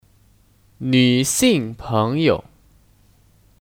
女性朋友 Nǚxìng péngyou (Kata benda): Teman perempuan